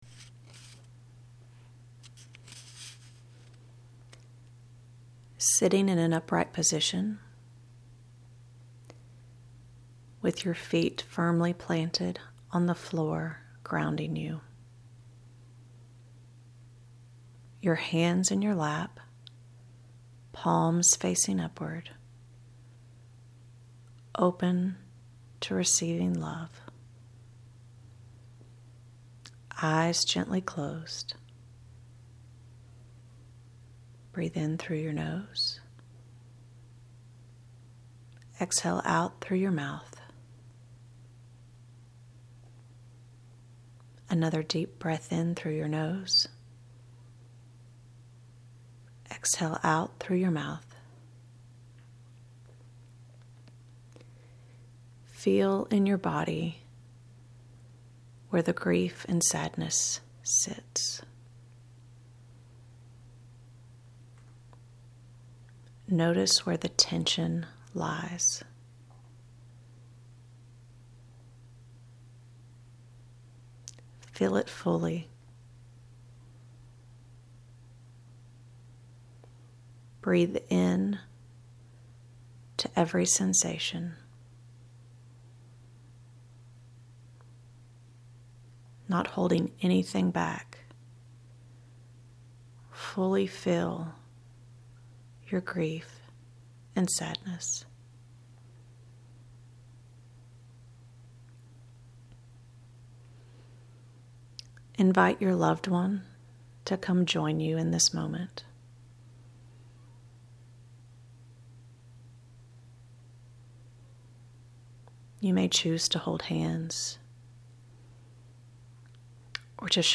guided meditation that I created for you.
Meditation+for+grief+during+holidays.MP3